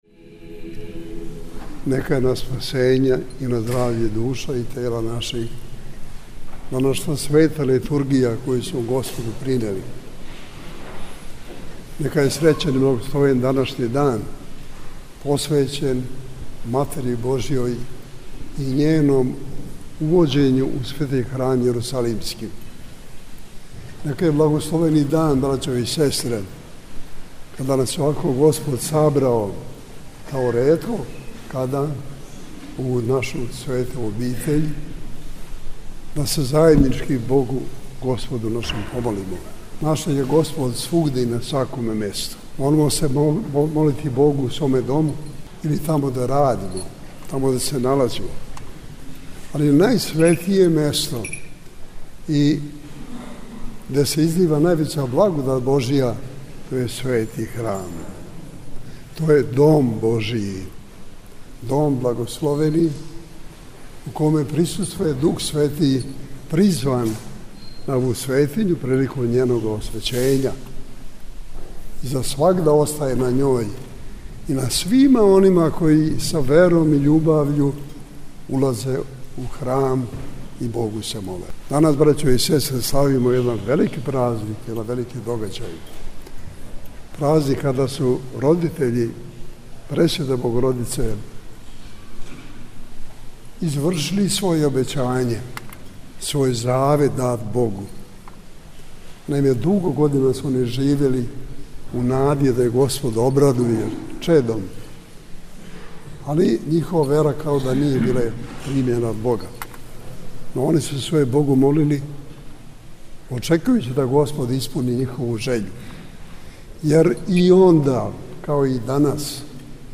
Његова Светост Патријарх српски г. Иринеј служио је овога јутра Свету архијерејску Литургију у Манастиру Ваведење на Сењаку, поводом славе ове београдске светиње.